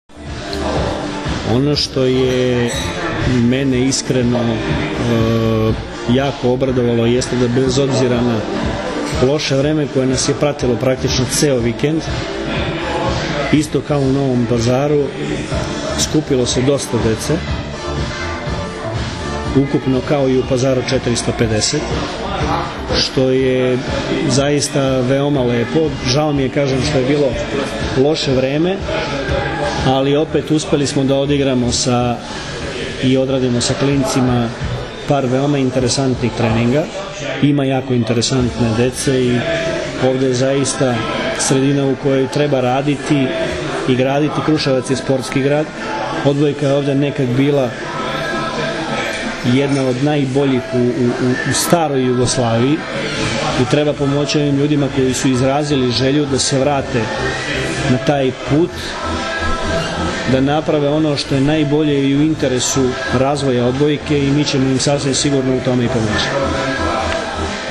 IZJAVA VLADIMIR GRBIĆA